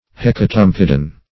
Search Result for " hecatompedon" : The Collaborative International Dictionary of English v.0.48: Hecatompedon \Hec`a*tom"pe*don\, n. [Gr.